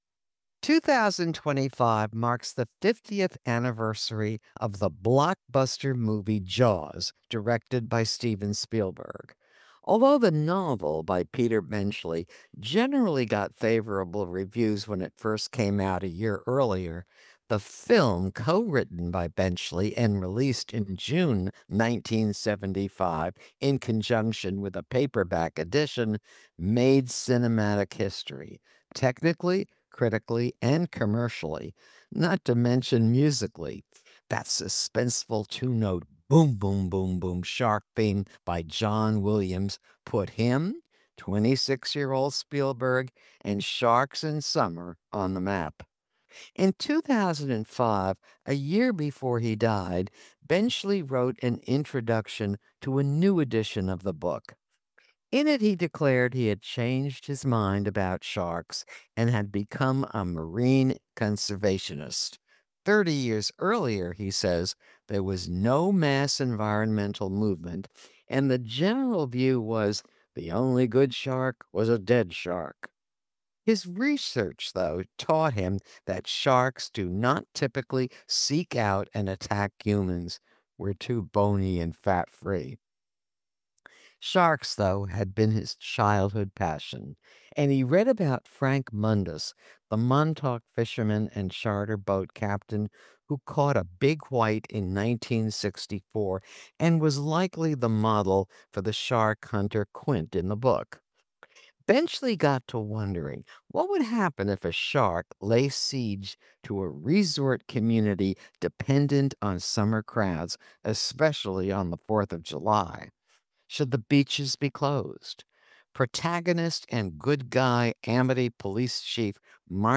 jaws-review-for-web.mp3